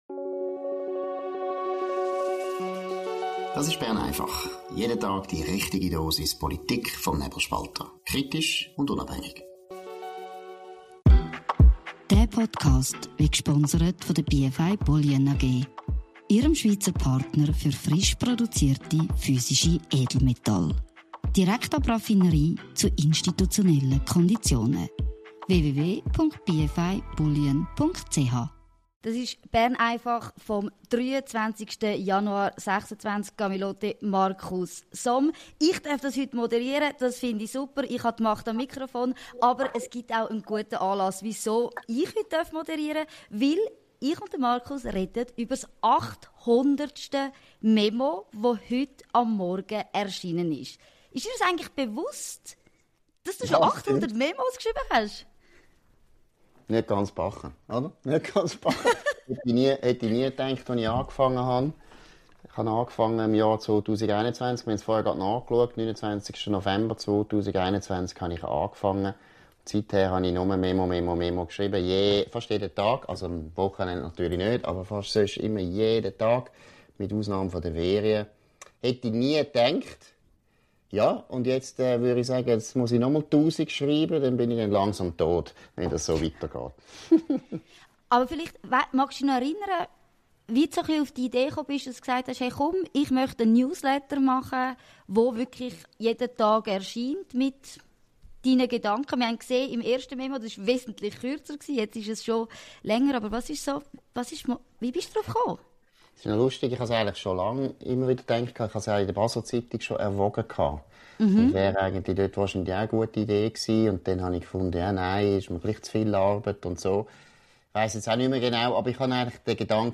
Spezial: 800 Mal Somms Memo – Chefredaktor Markus Somm im Gespräch